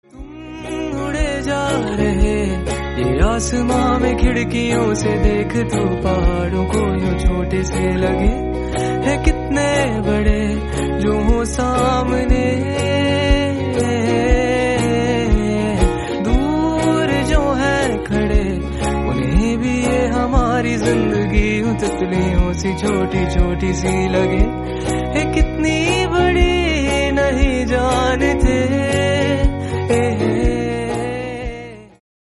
If you’re searching for the perfect soothing ringtone
calm and emotional vibe